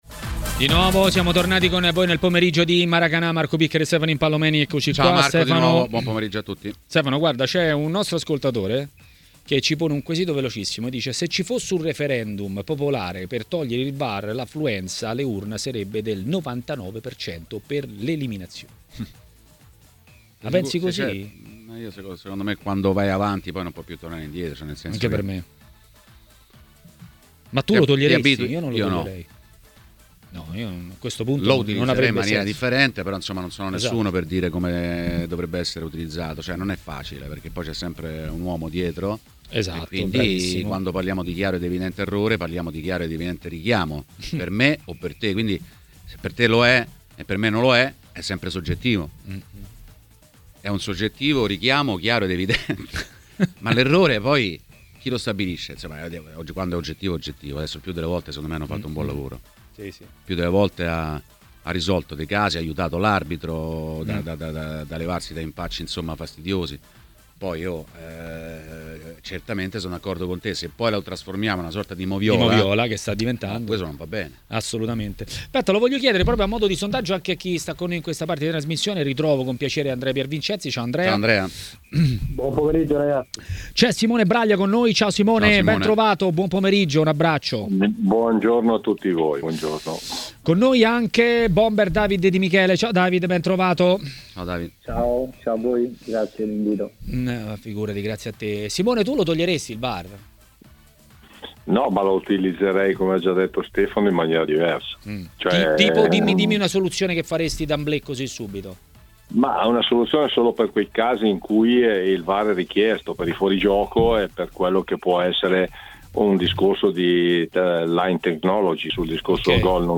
A parlare dei temi del giorno a TMW Radio, durante Maracanà, è stato l'ex portiere Simone Braglia.